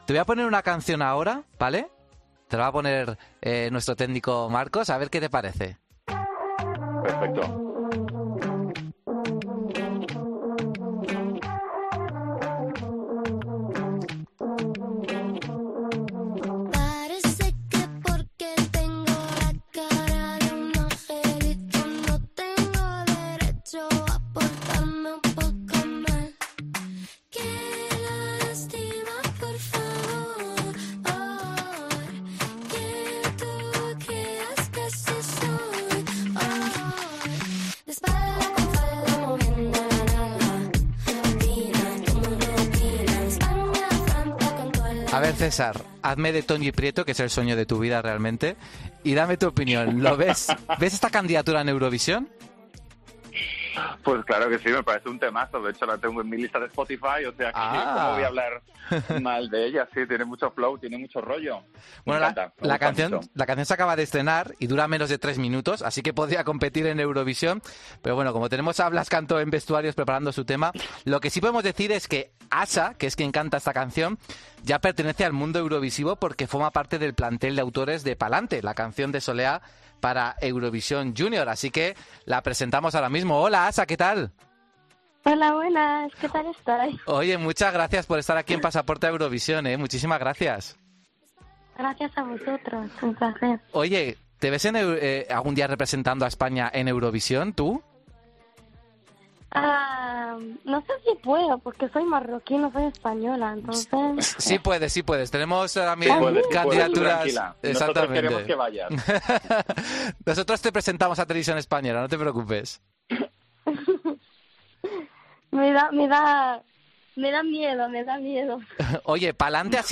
En una entrevista en el programa "Pasaporte a Eurovisión" de COPE, la cantante también se ha deshecho en elogios hacia Soleá.